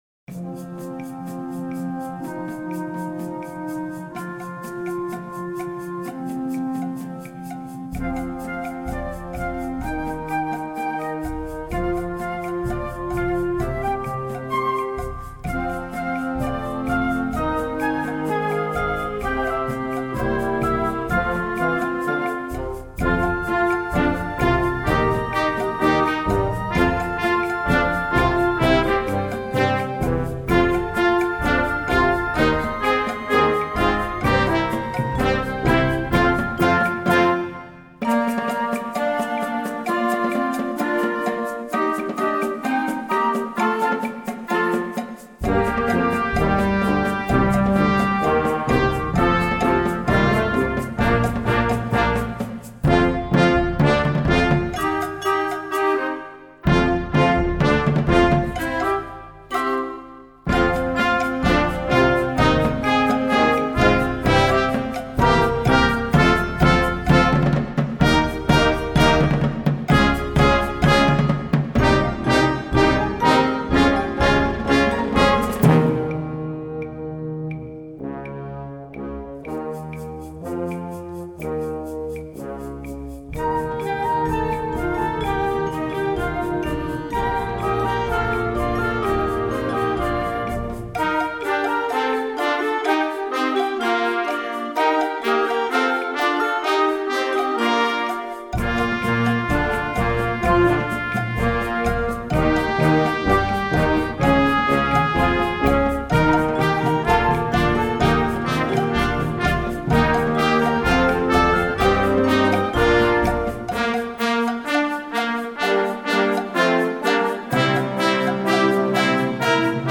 Voicing: Percussion Section w/ Band